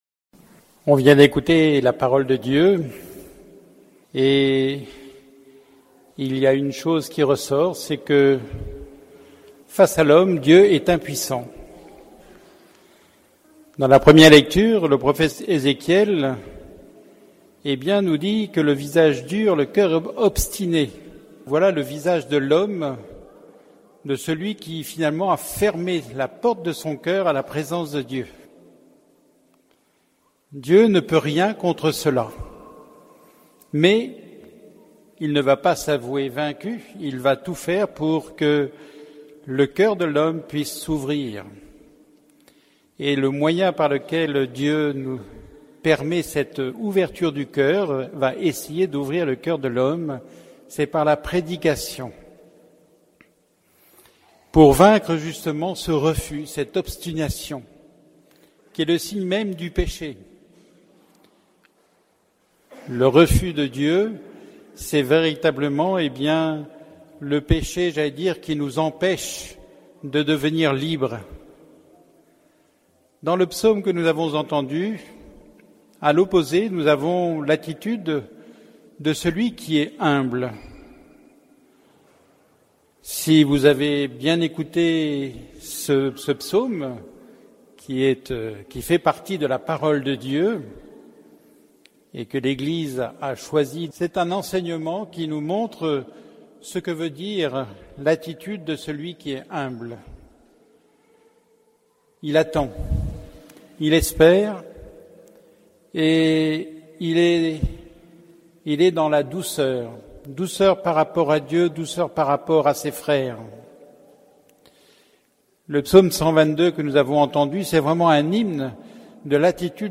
Homélie du 14e dimanche du Temps Ordinaire